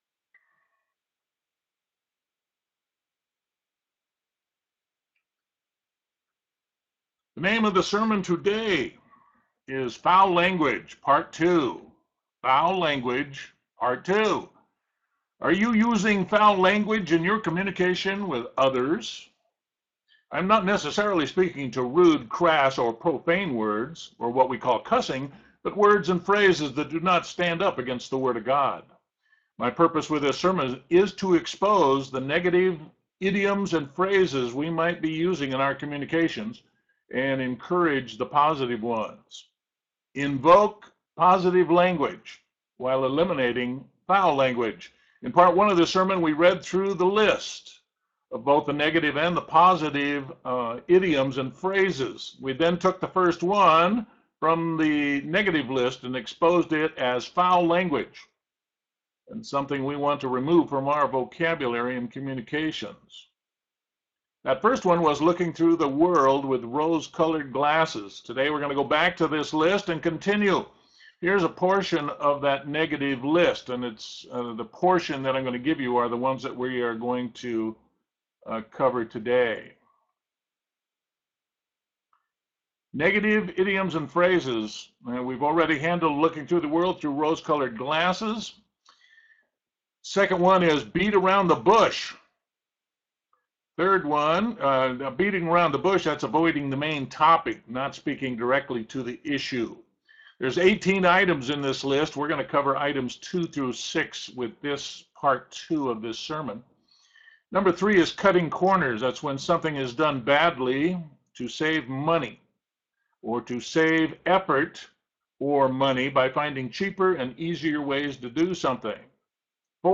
Sermon: Foul Language - Part 4